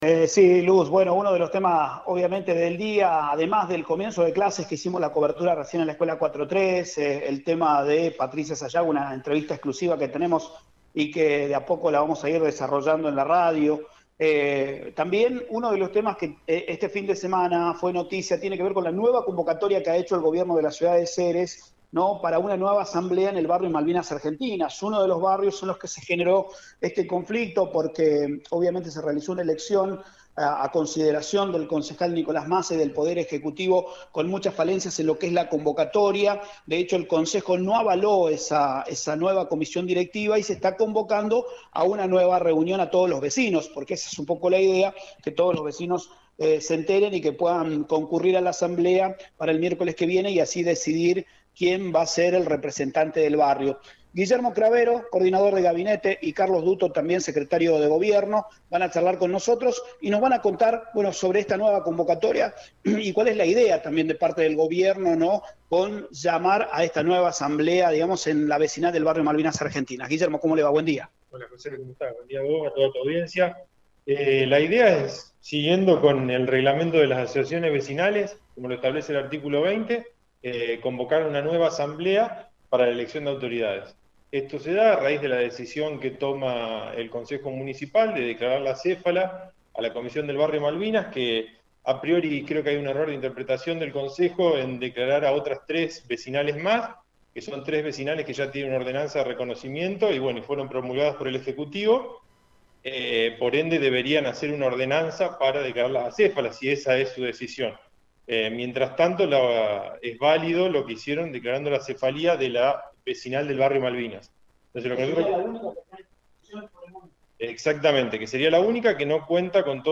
El Coordinador de Gabinete Guillermo Cravero, y Carlos Dutto Secretario de Gobierno hablaron en Radio Eme Ceres, quienes hicieron referencia de la nueva convocatoria de Asamblea en el Barrio Malvinas Argentinas.